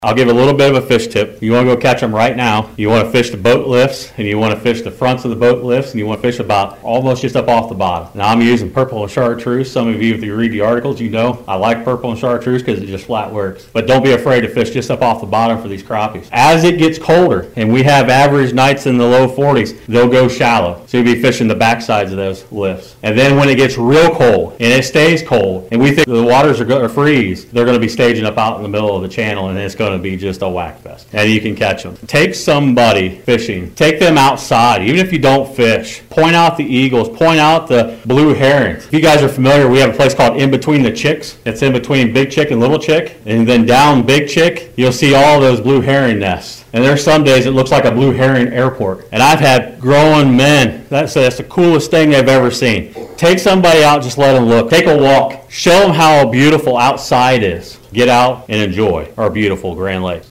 Lake Improvement Association holds November Meeting